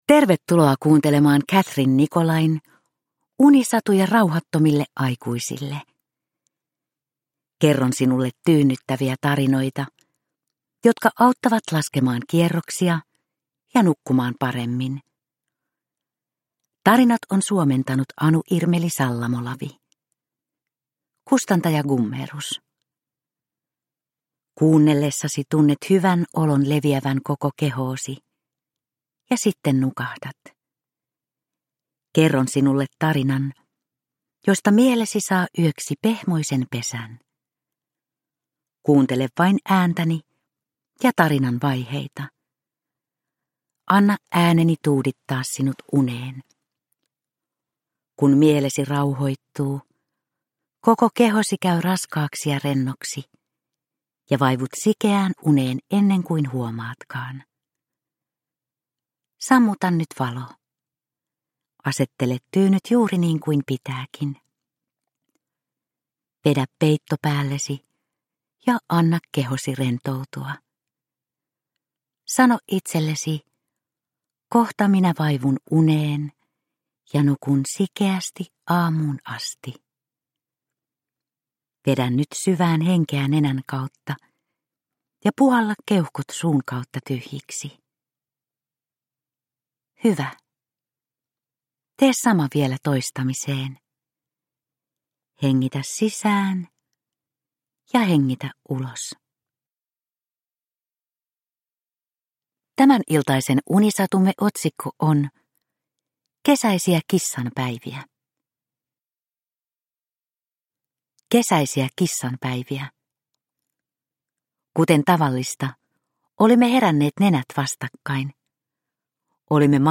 Unisatuja rauhattomille aikuisille 43 - Kesäisiä kissanpäiviä – Ljudbok – Laddas ner
Uppläsare: Vuokko Hovatta